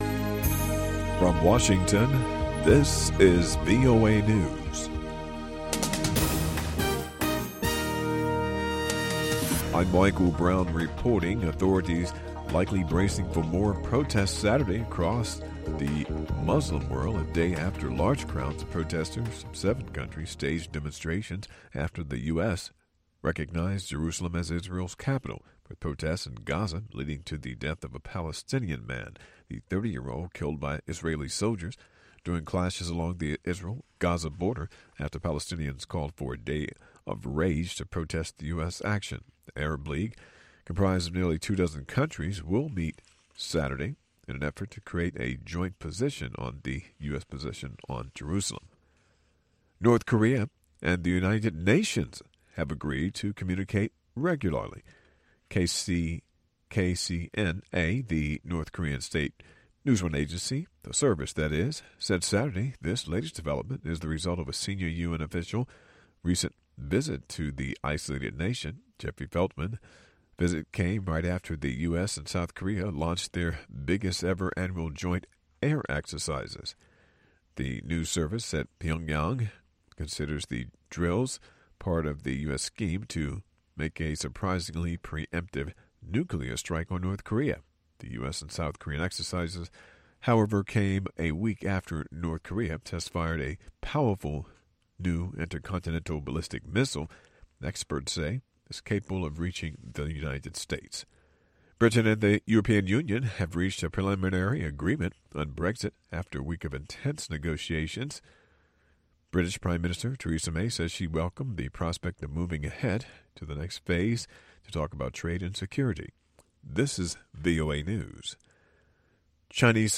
You'll hear neo-traditional music from South Africa, Angola, and Mali; a classic IJAW song in the middle of the hour; and some of the best current dance tunes Africa has to offer! The showstopper is a nice, long chat with the world renown Senegalese artist Baaba Maal! At :35 into the show Baaba talks about his own music festival Blues by the River and most recent album Traveler.